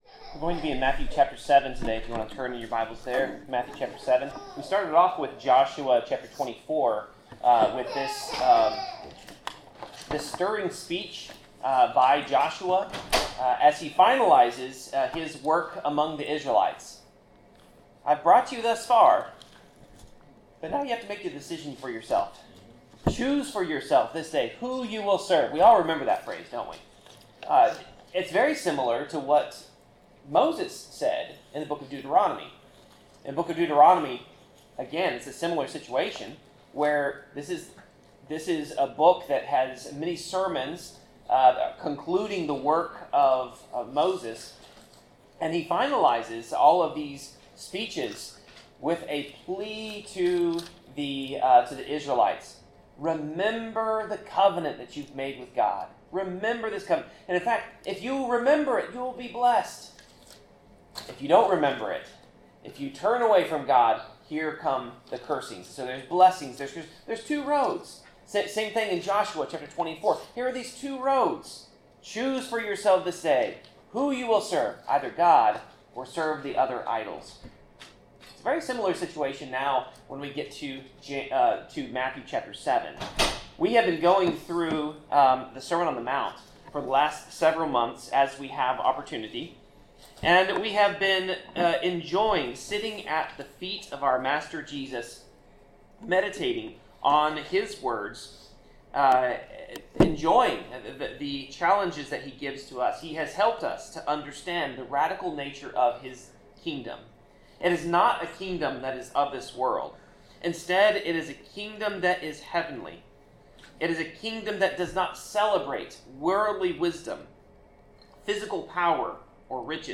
Passage: Matthew 7:13-29 Service Type: Sermon